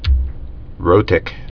(rōtĭk)